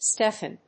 /ˈstɛfʌn(米国英語), ˈstefʌn(英国英語)/